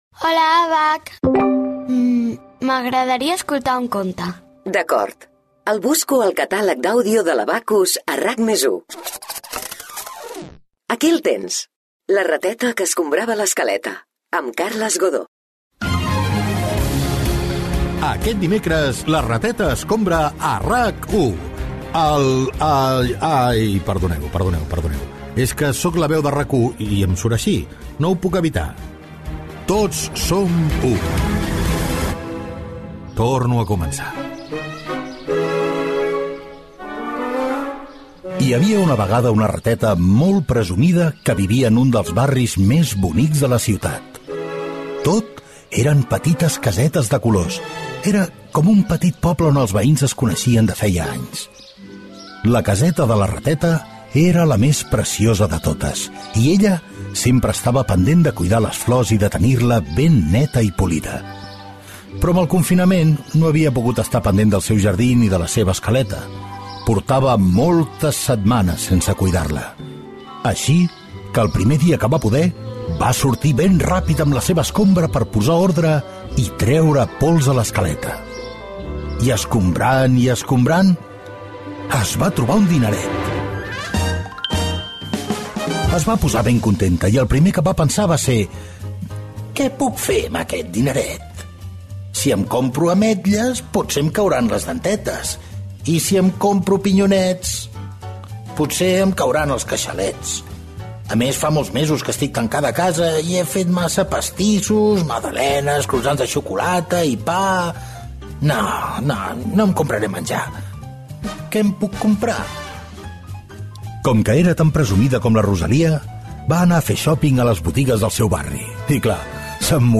Careta del programa. Versió radiofònica de l'adaptació del conte "La rateta que escombrava l'escaleta". Publicitat
Infantil-juvenil